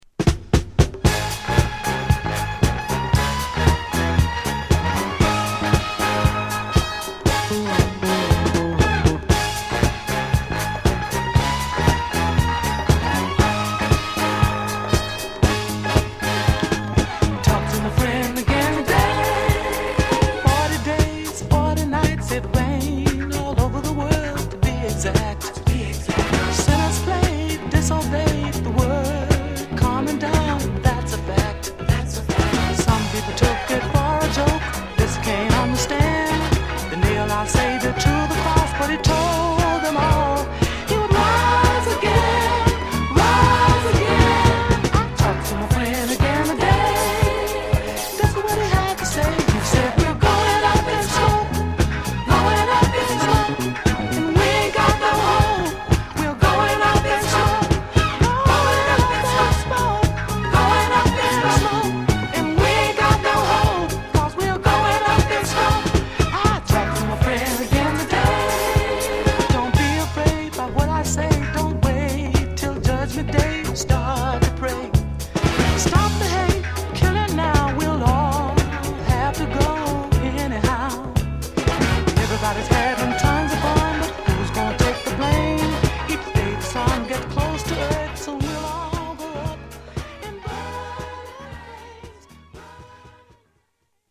高揚感溢れるイントロからグルーヴィーで伸びやかに展開する、数々の著名DJ達がなおプレイするのも納得のダンストラック名曲！